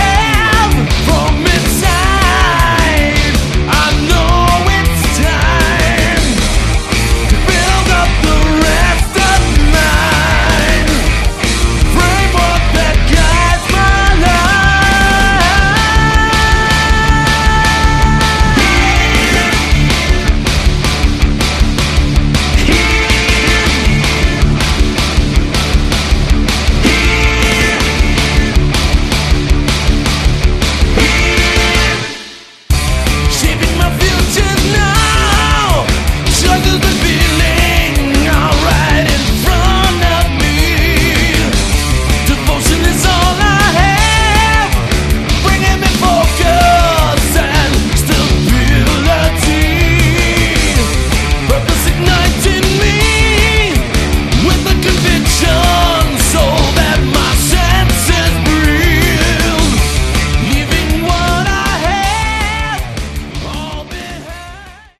Category: Melodic, Progressive
vocals, guitars, bass, drum programming
bass and Moog pedals